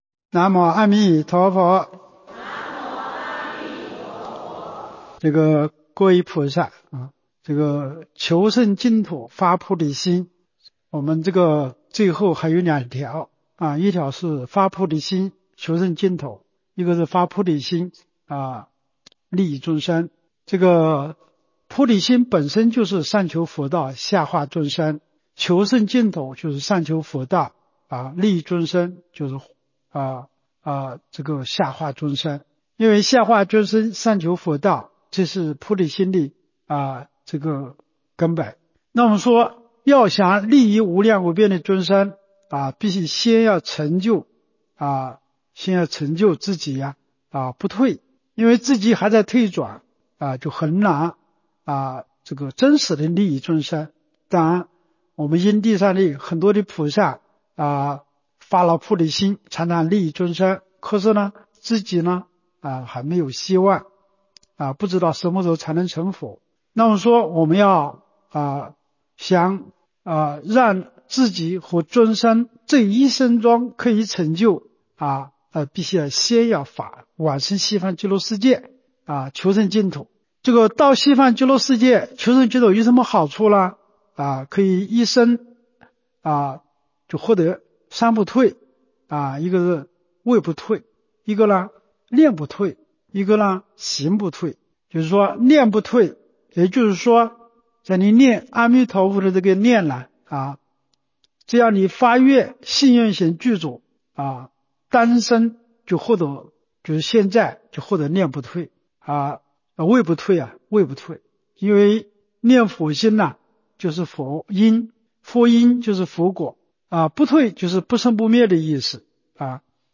彭泽集福寺佛七开示